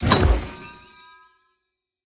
open_shutter.ogg